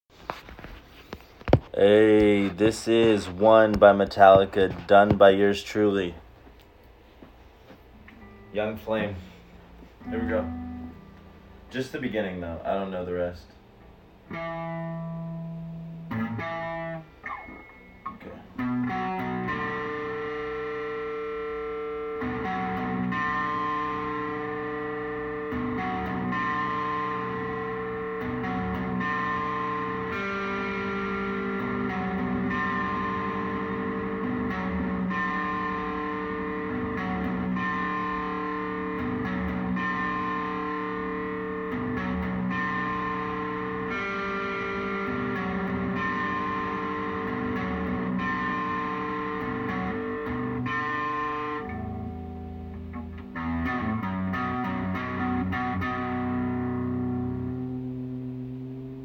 Guitar Skills Sound Effects Free Download